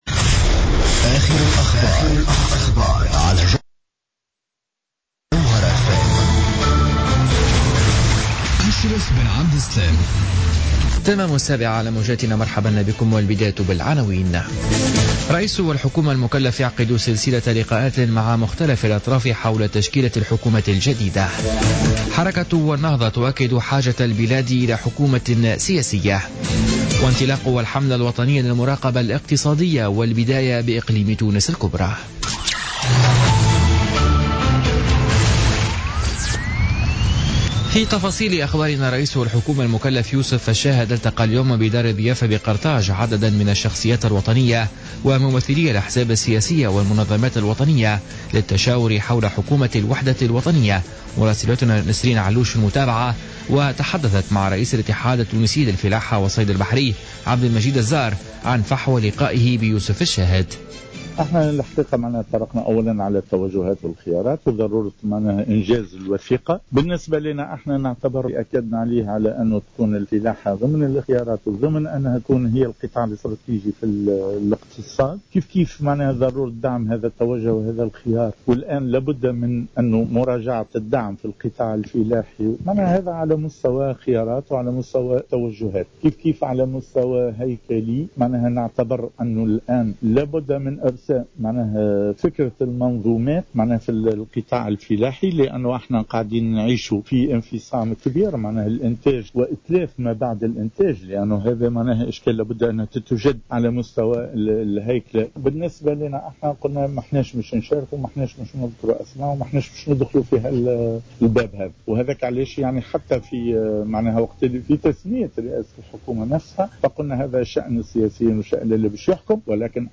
نشرة أخبار السابعة مساء ليوم الخميس 4 أوت 2016